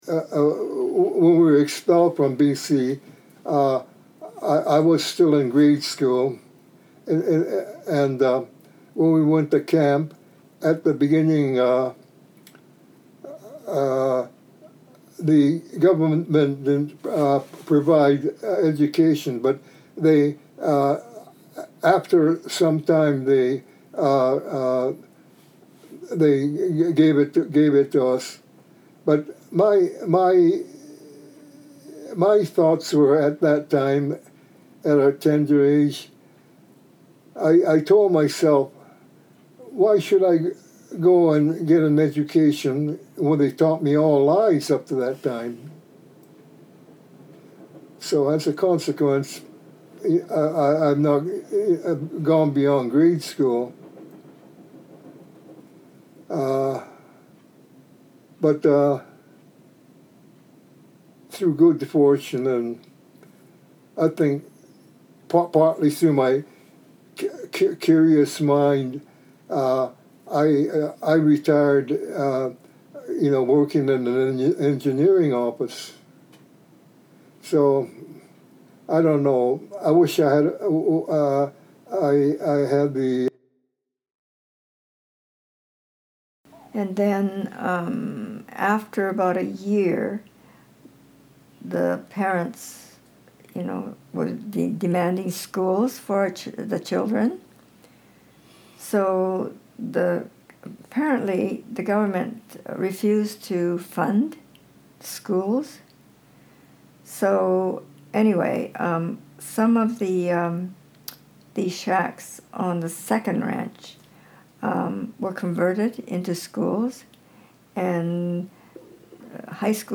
Oral Histories
Listen to stories told by Japanese Canadians relating to objects in the Journeys Education Kit.